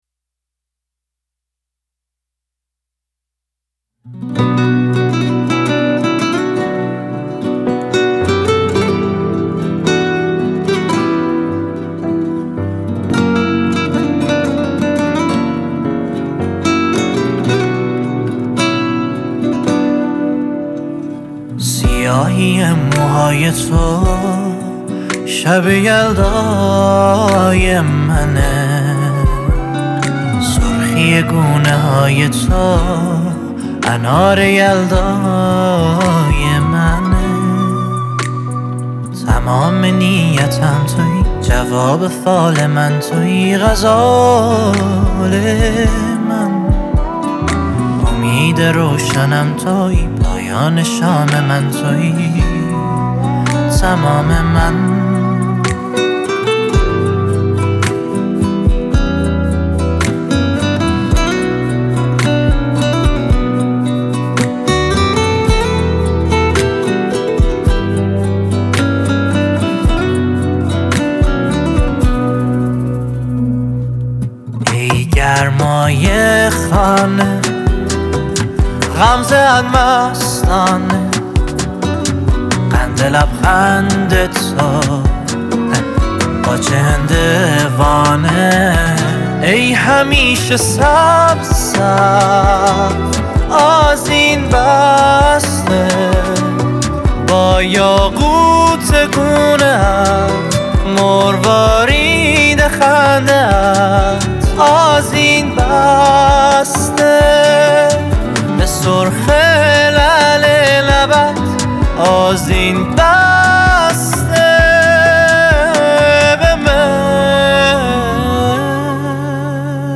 پادکست : تک آهنگ
دسته : پاپ